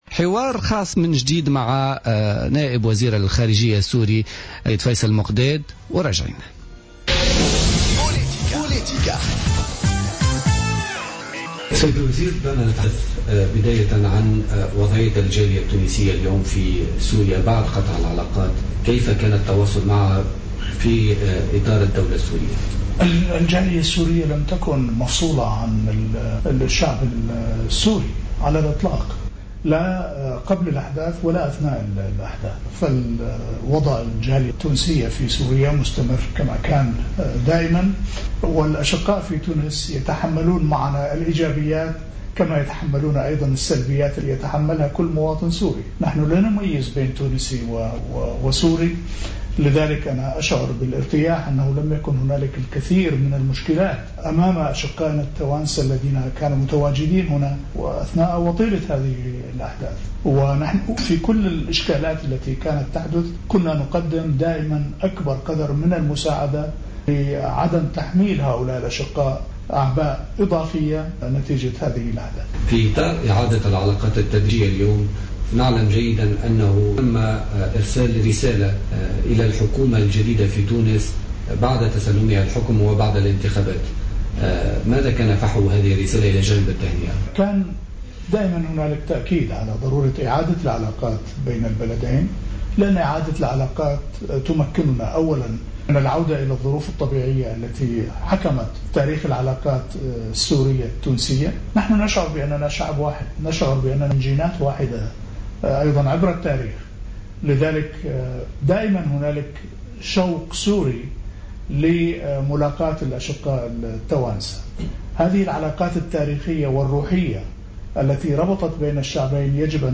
واعتبر في تفي تصريح للجوهرة "أف أم" اليوم الخميس خلال برنامج بوليتيكا على هامش بعثة تونسية شاركت فيها إذاعة "الجوهرة"، أن قرار المقاطعة الذي اتخذه "الجاحدون" لن يؤثّر على العلاقة العميقة بين الشعبين، بحسب تعبيره.